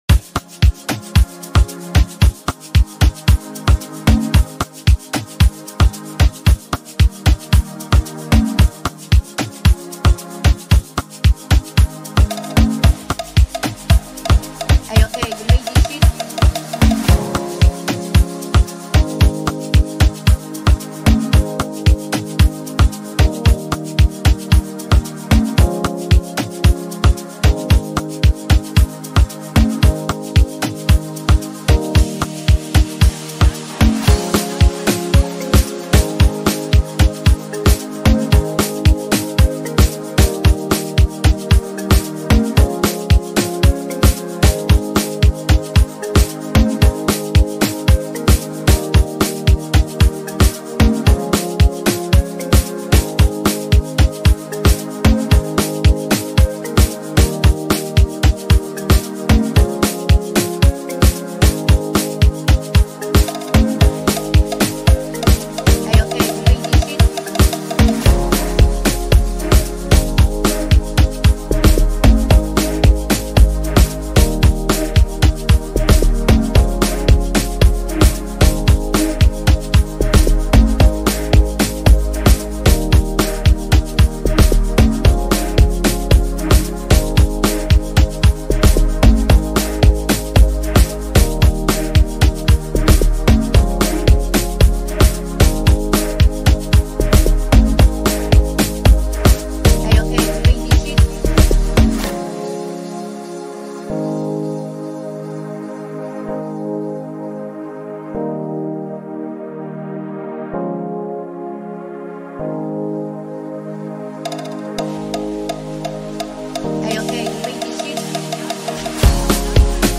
Home » Amapiano » Gqom » Lekompo